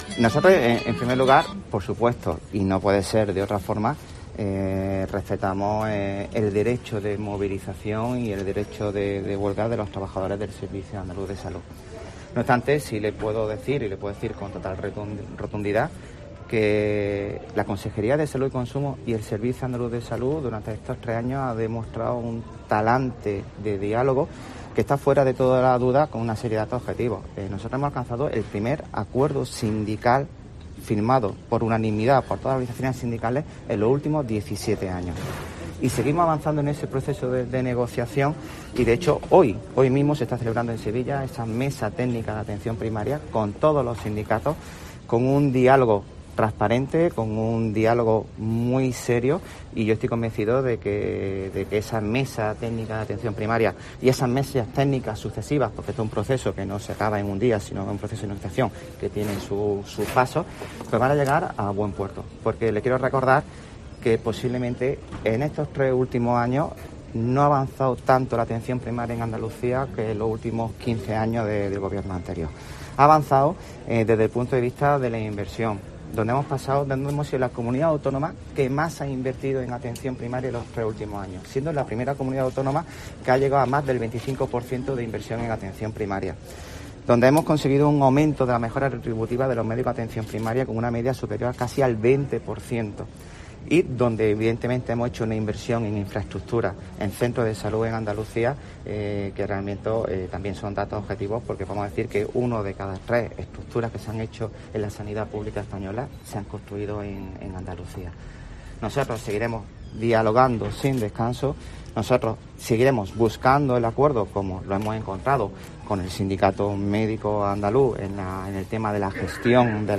En este sentido y en declaraciones a los periodistas durante una visita a la nueva Unidad de Cuidados Respiratorios Intermedios y al Hospital de Día de Neumología del Reina Sofía de Córdoba, Vargas ha dicho respetar "el derecho de movilización y el derecho de huelga de los trabajadores" del SAS, que este mismo jueves, convocados por CCOO, UGT y CSIF, se han movilizado para reclamar cambios en la gestión de la Atención Primaria en Andalucía.